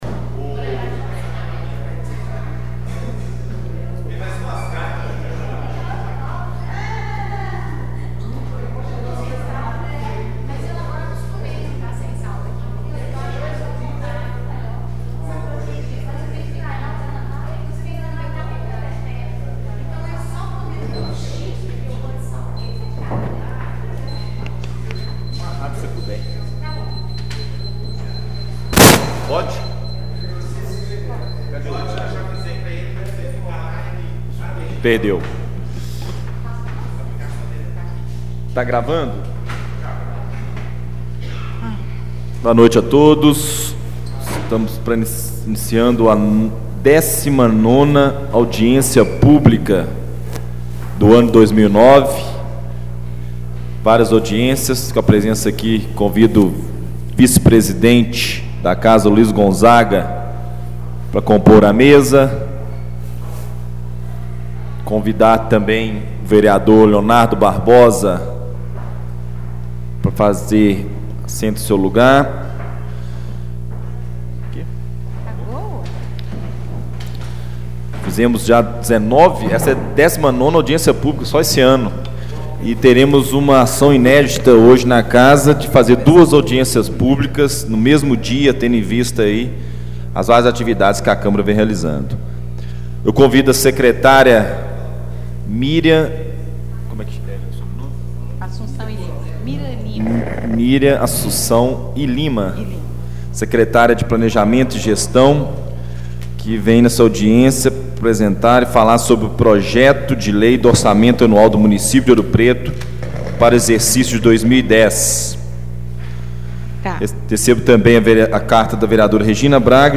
Audiência Pública da Câmara Municipal: Projeto de Lei, do Orçamento Anual do município de Ouro Preto para o exercício de 2010 e Plano Plurianual para o exercício de 2010/2013 Reunião Compartilhar: Fechar